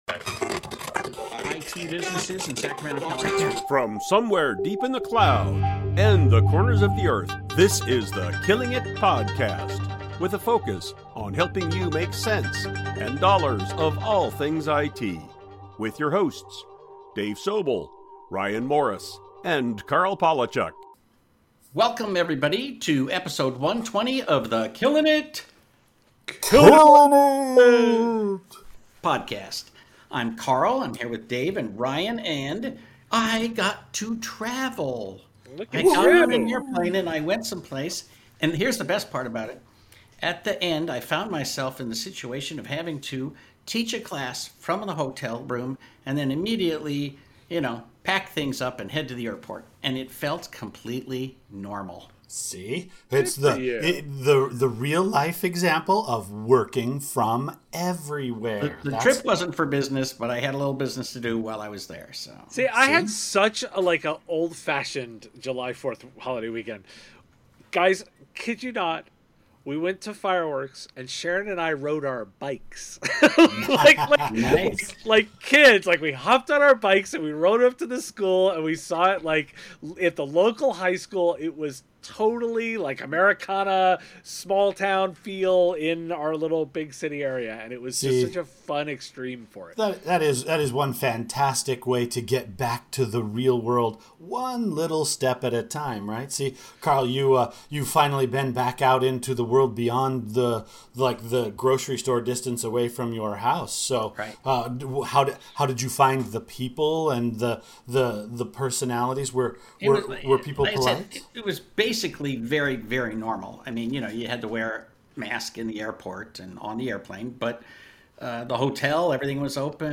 Episode 120 - Riffing on Cybersecurity and the IT Industry After the Kaseya attack, there really was nothing else to talk about. In a slightly different format, we take the full show to just enjoy a totally unrehearsed discussion of the state of cybersecurity and what it means for the IT industry.